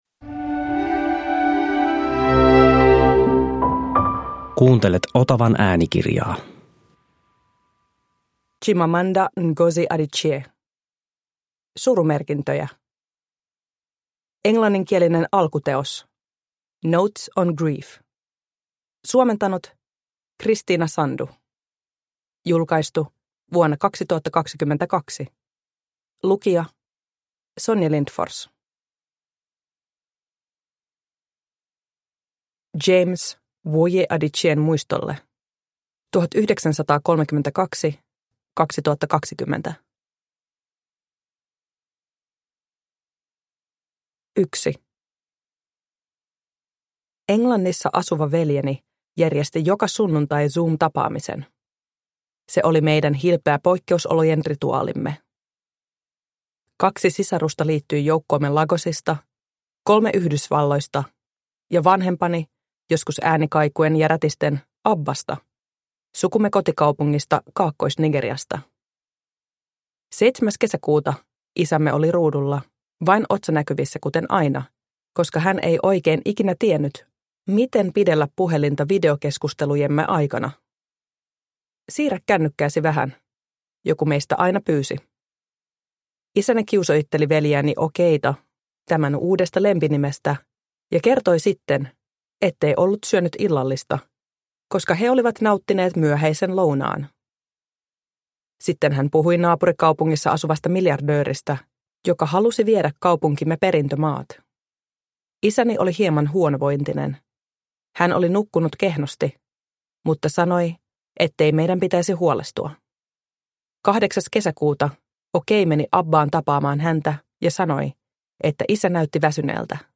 Surumerkintöjä – Ljudbok – Laddas ner